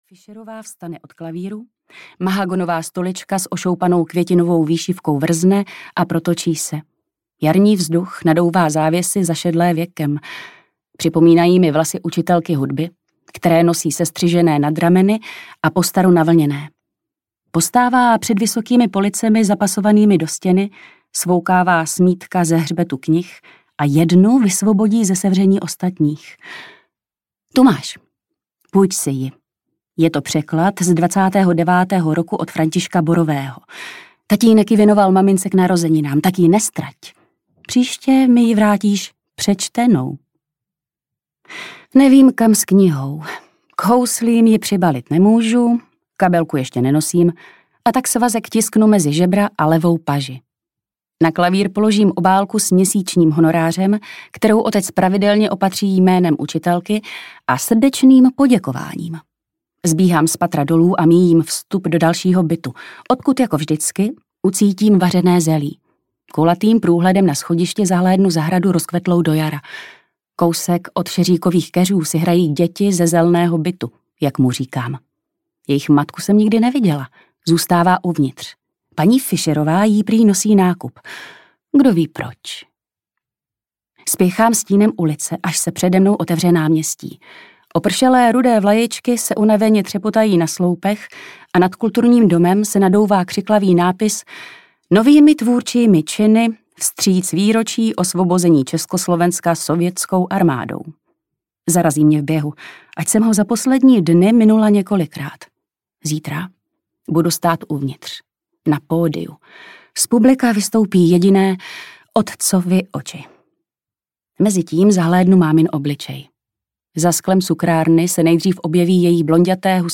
Dvojí život audiokniha
Ukázka z knihy